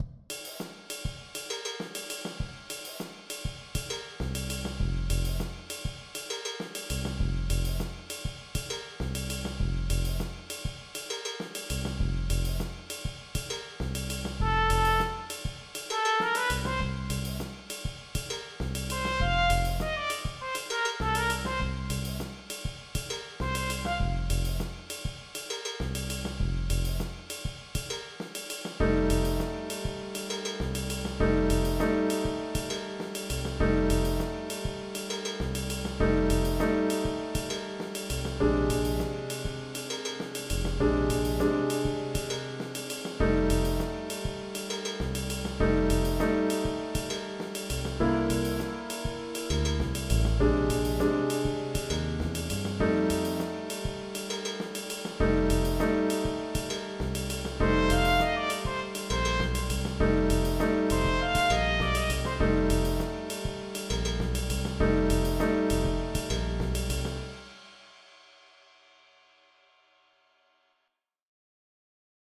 Für Ensemble
Ensemblemusik